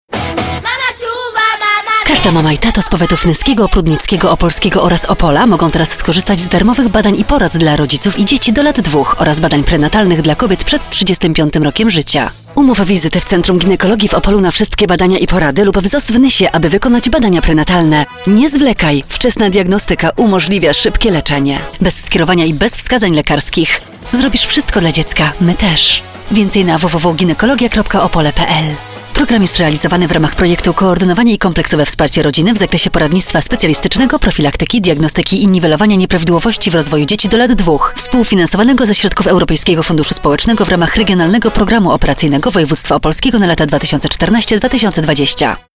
spot_radiowy_nr_1_-_zaakceptowany_30.05..mp3